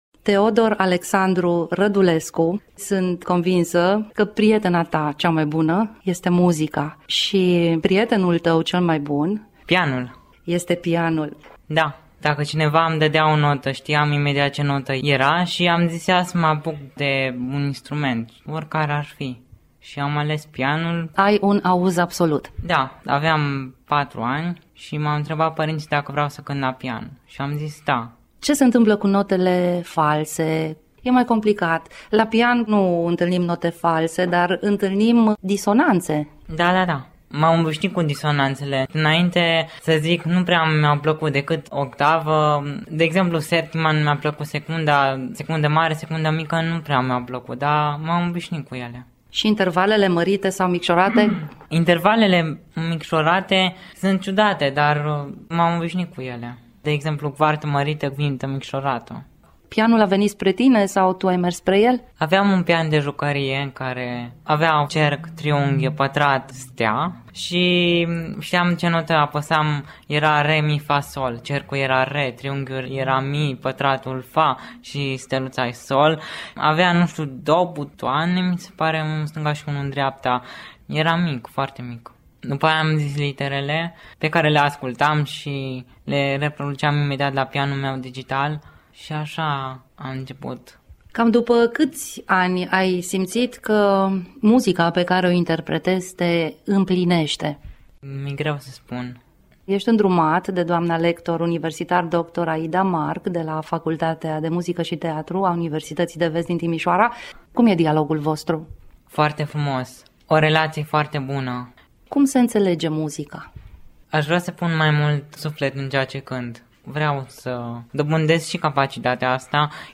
AUDIO/ Interviu Radio Timișoara